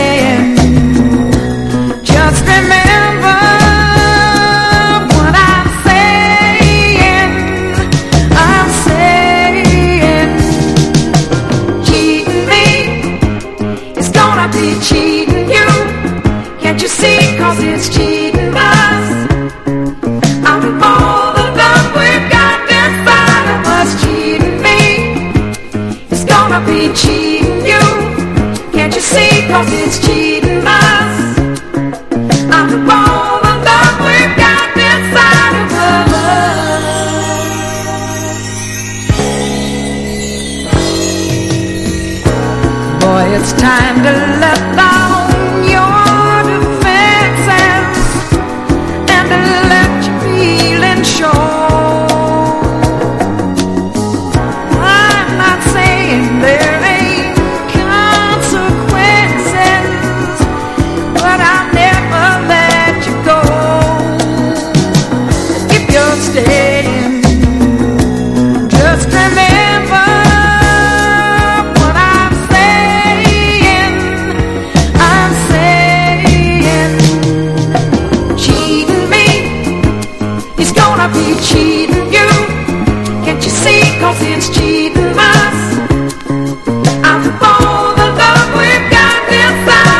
AOR